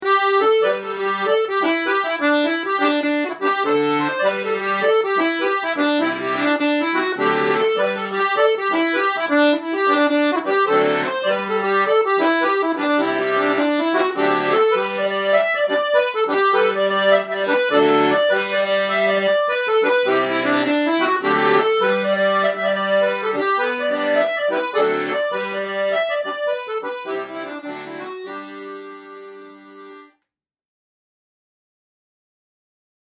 Piano Accordion - Easy Online Lessons- OAIM
Piano-Accordion.mp3